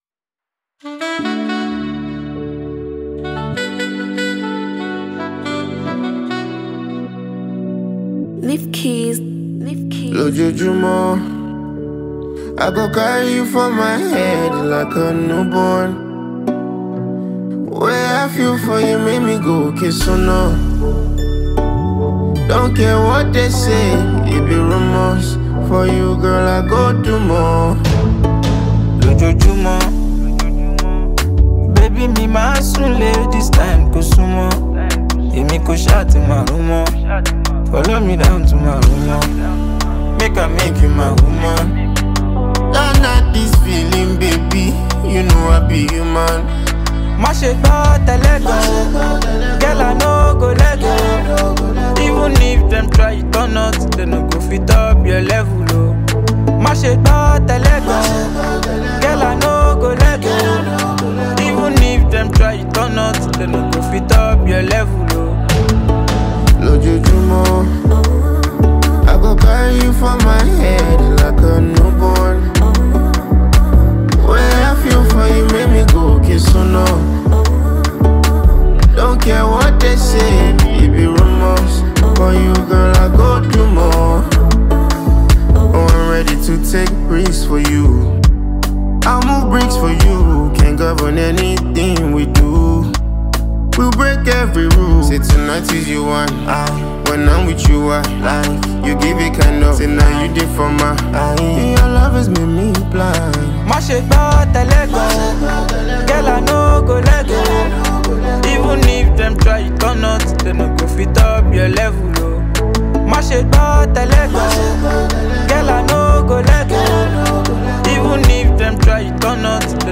Afro Fuji Pop, Highlife
Yoruba Fuji song